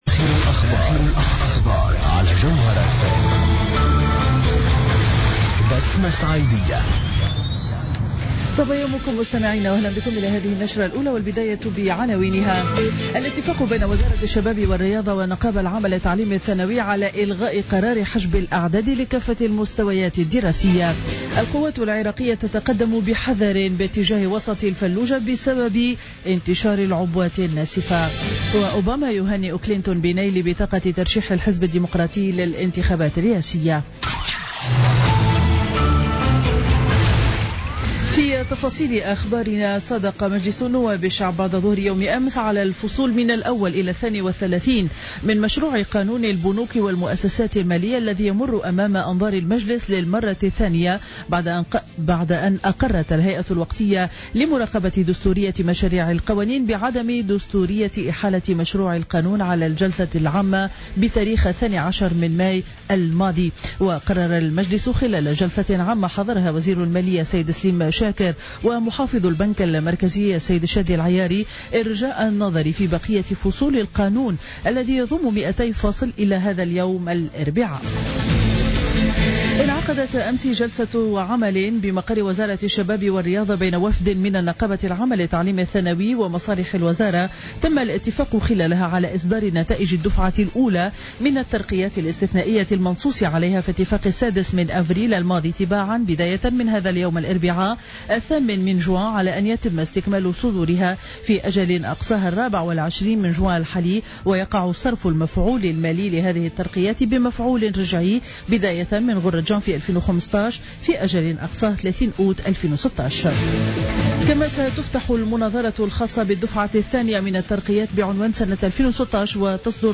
نشرة أخبار السابعة صباحا ليوم الأربعاء 8 جوان 2016